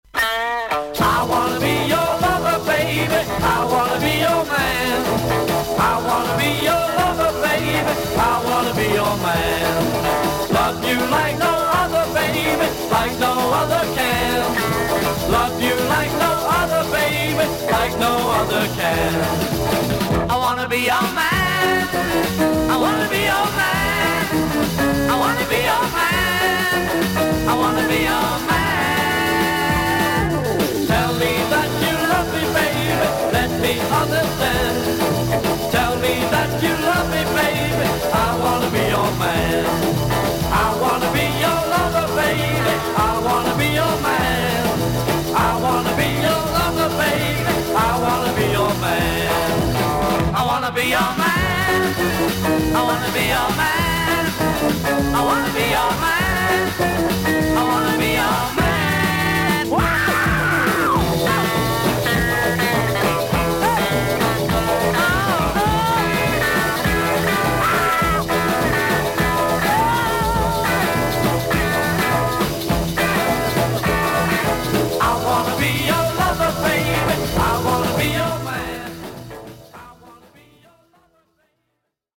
ほんの少々サーフィス・ノイズあり。クリアな音です。B4〜B7に浅い細いスジが見えますがノイズは感じませんでした。